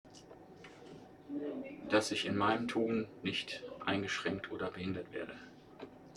MS Wissenschaft @ Diverse Häfen
Der Anlass war MS Wissenschaft